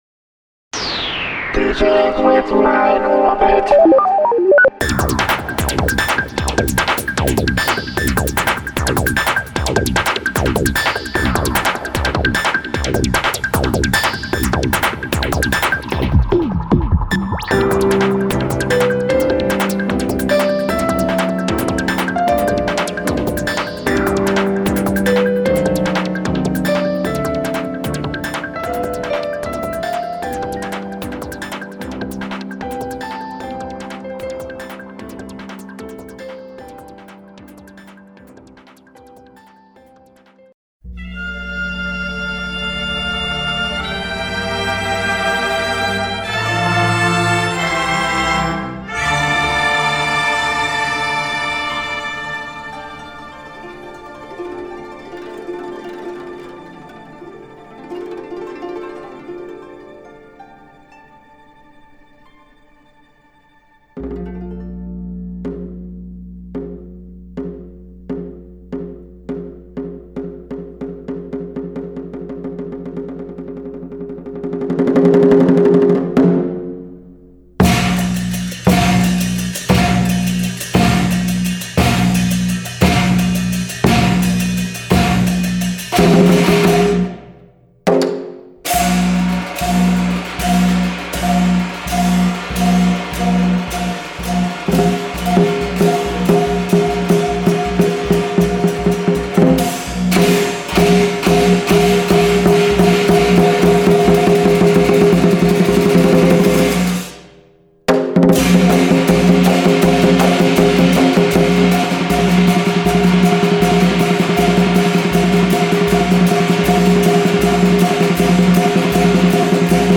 We are sorry for little bit worse sound quality.